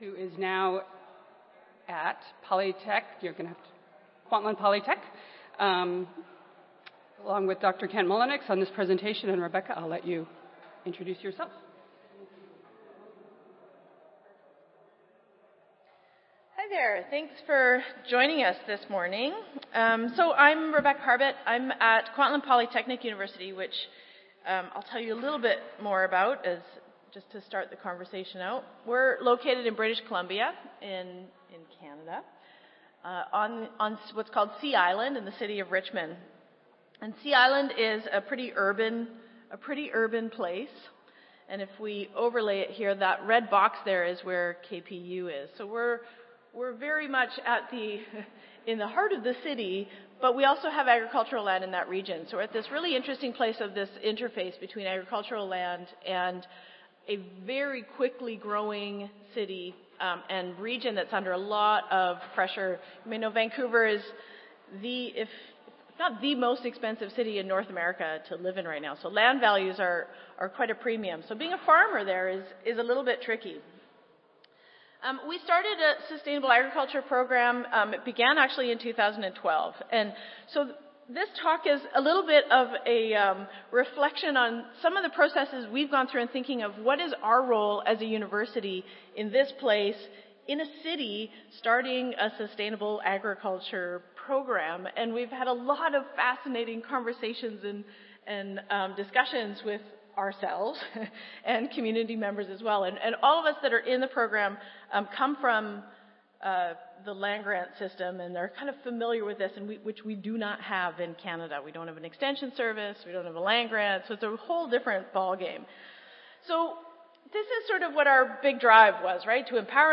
Kwantlen Polytechnic University Audio File Recorded Presentation